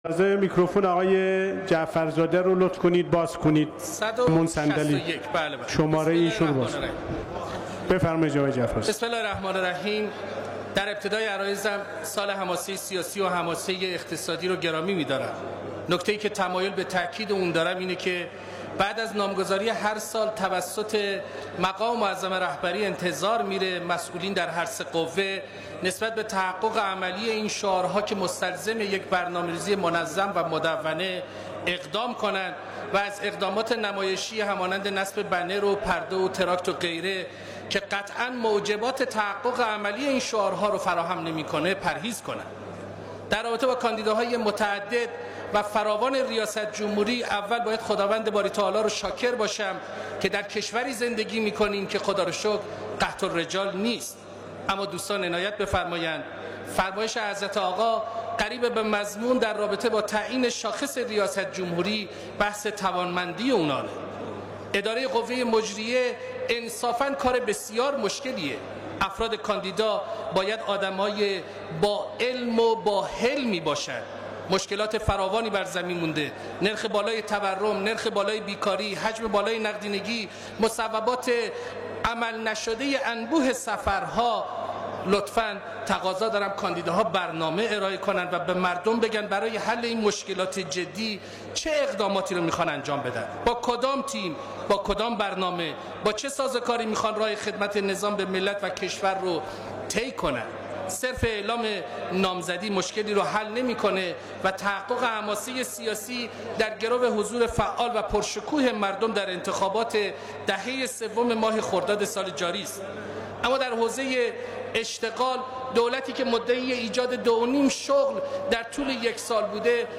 به گزارش دیارمیرزا : غلامعلی جعفرزاده ایمن‌آبادی در نشست علنی امروز (یکشنبه یکم اردیبهشت) مجلس شورای اسلامی در نطق میان دستور خود ضمن گرامیداشت سال حماسه سیاسی و اقتصادی، گفت: بعد از نامگذاری هر سال از سوی مقام معظم رهبری انتظار می‌رود مسئولان در سه قوه نسبت به تحقق عملی این شعارها که مستلزم تدوین یک برنامه‌ریزی مدون است اقدام کنند و اقدامات نمایشی همانند تراکت‌ها و بنر جدا خودداری کنند.